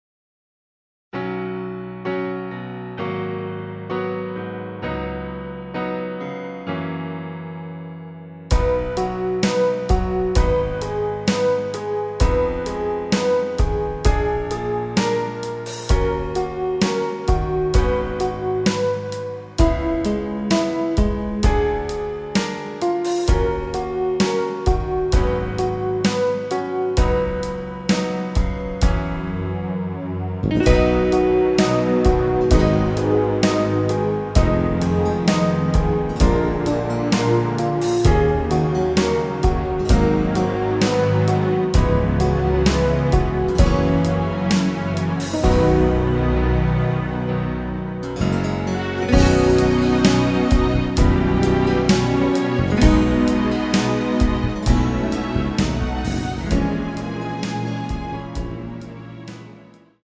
심플한 MR
앞부분30초, 뒷부분30초씩 편집해서 올려 드리고 있습니다.
중간에 음이 끈어지고 다시 나오는 이유는